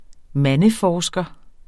Udtale [ ˈmanə- ]